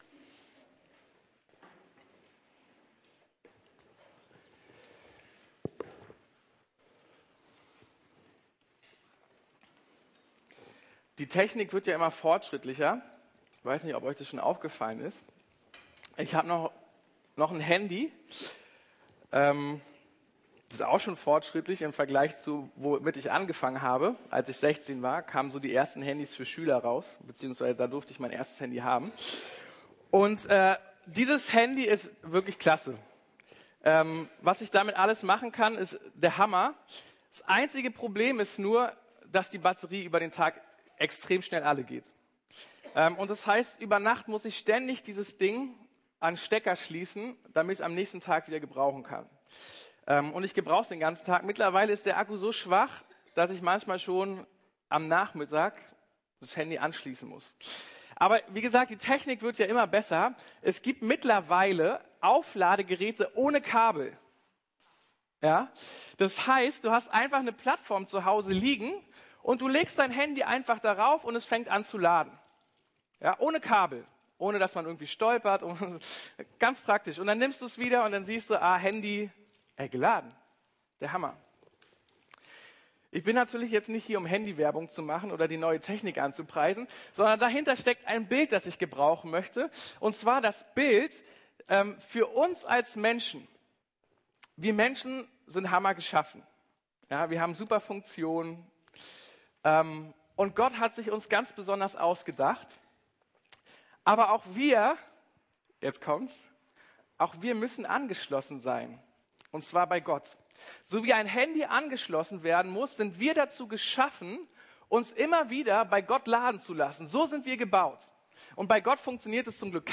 Wie ich Gott begegne ~ Predigten der LUKAS GEMEINDE Podcast